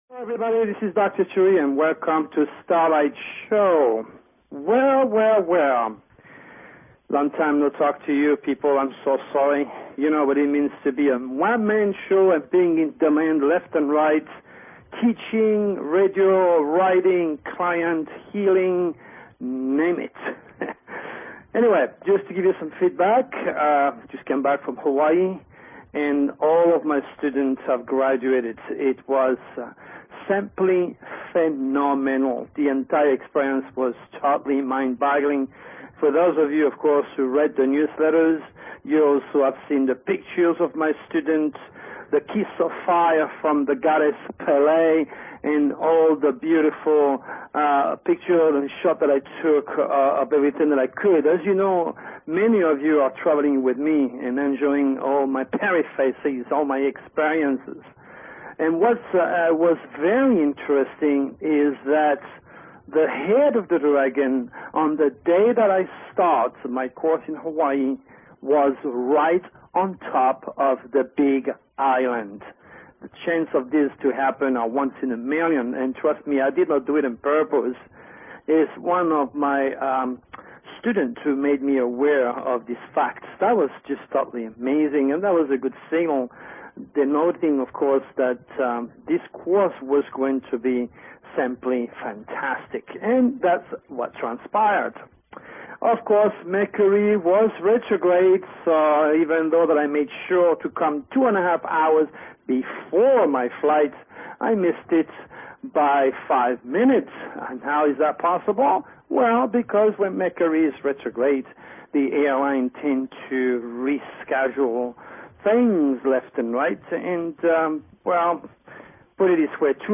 Talk Show Episode, Audio Podcast, Starlight_Radio and Courtesy of BBS Radio on , show guests , about , categorized as